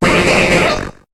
Cri de M. Mime dans Pokémon HOME.